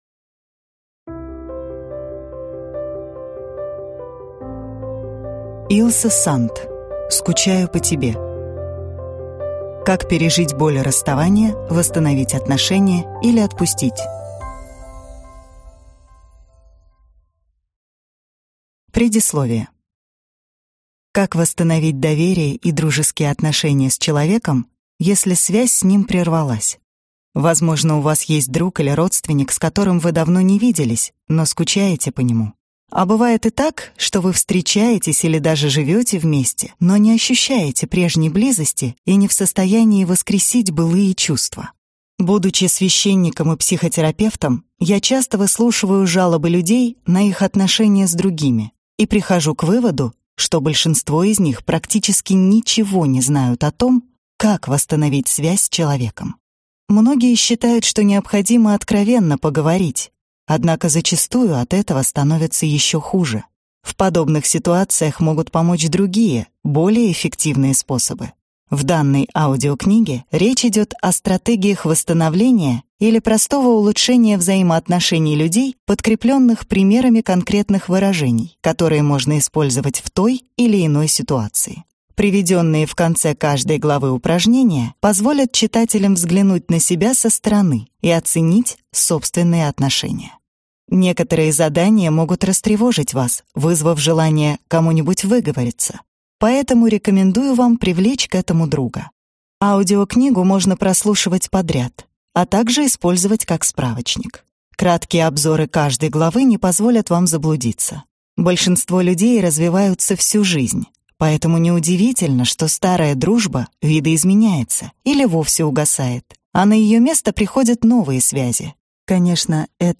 Аудиокнига Скучаю по тебе | Библиотека аудиокниг